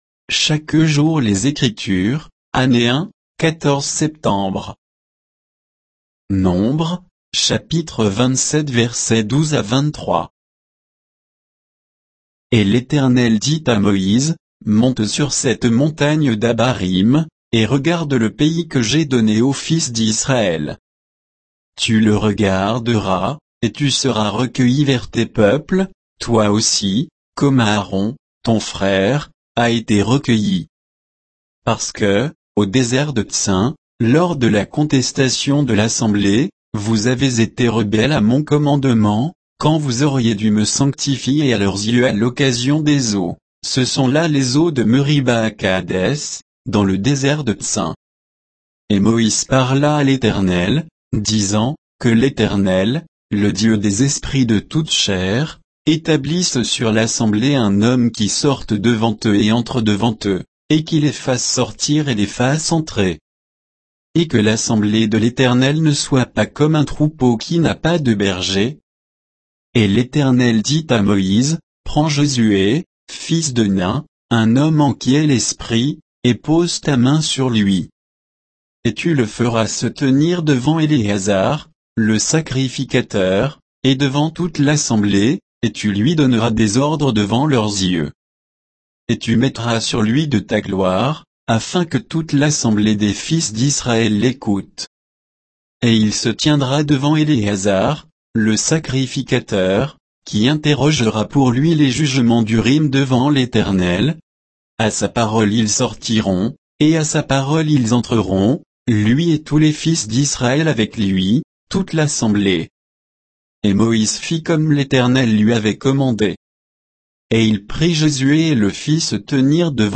Méditation quoditienne de Chaque jour les Écritures sur Nombres 27